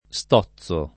stozzo [ S t 0ZZ o ]